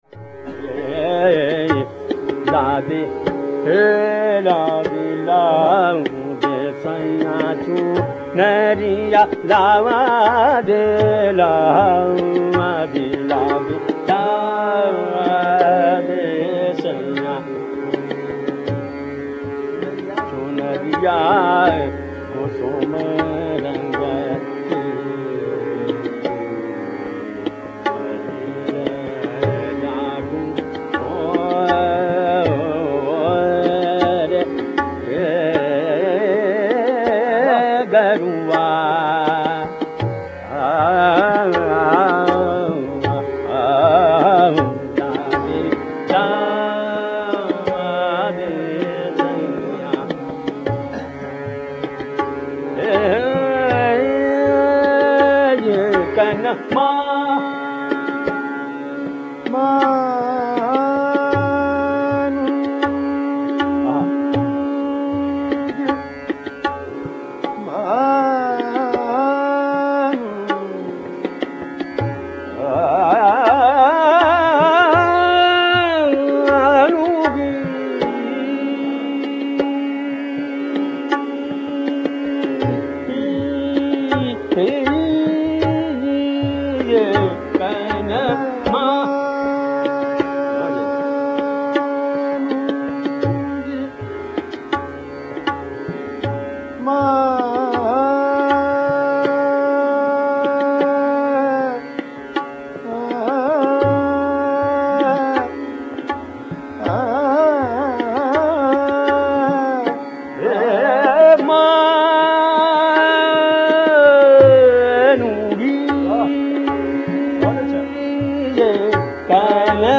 Raga Shuddha Kalyan/Bhoop Kalyan
His exquisite vilambit Roopak composition: